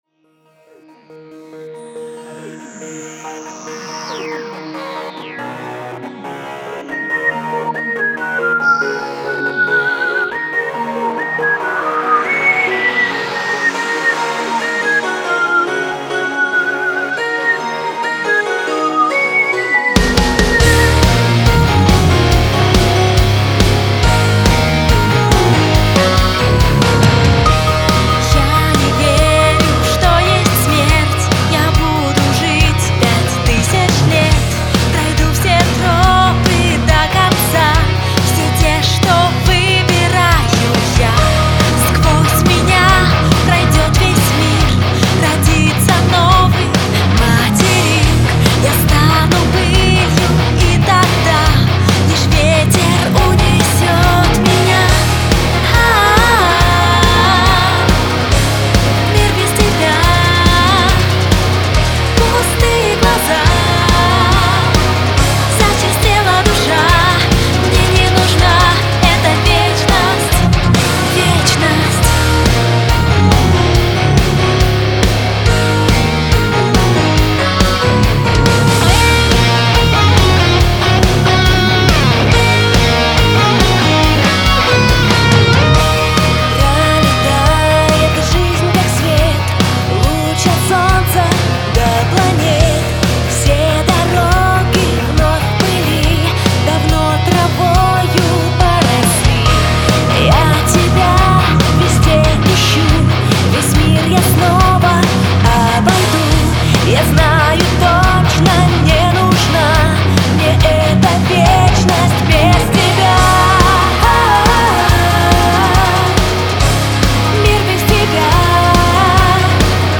Женский метал на зацен...
Комрады, свожу тут один группешник, с барышнями на вокалах....